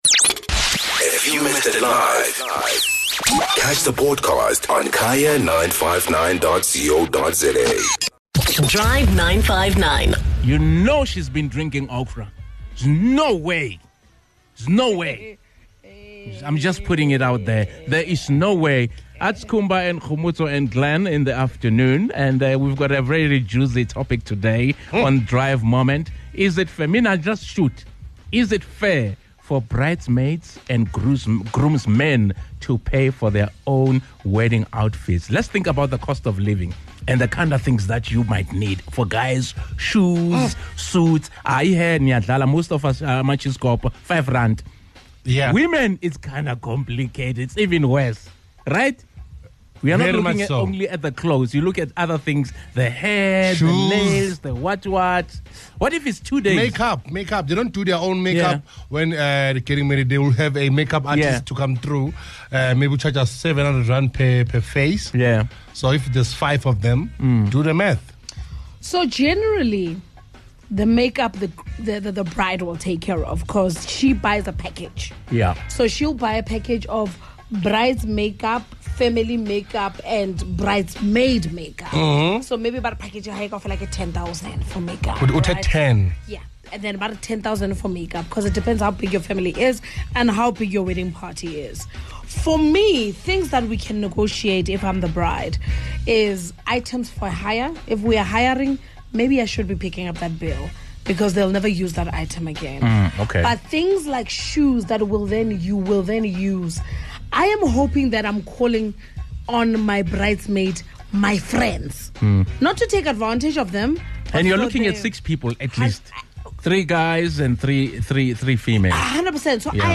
Should the responsibility of this fall on the bridesmaids and groomsmen as individuals, or on the couple that is getting married? We heard the Drive 959 listeners as they weighed in!